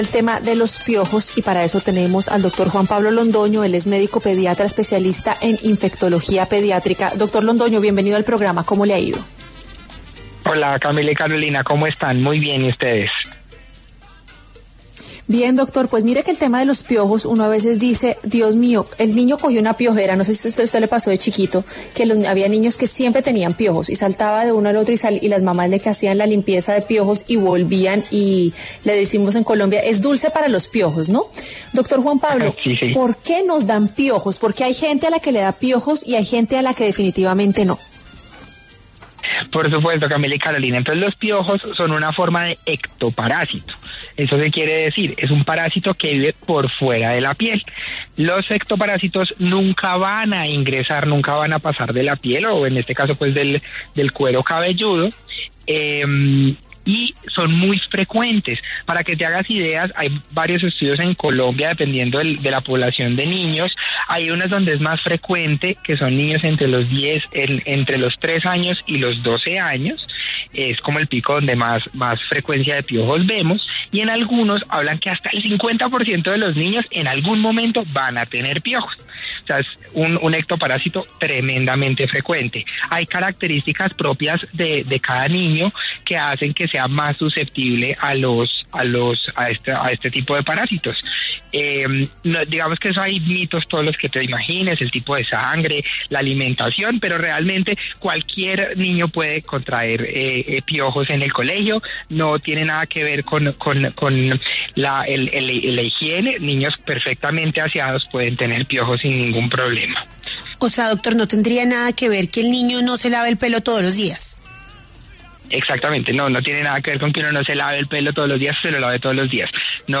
El infectólogo pediatra